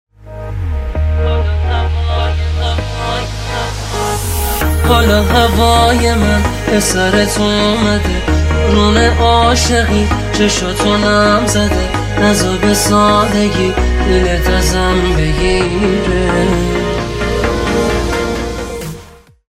زنگ موبایل
رینگتون احساسی و باکلام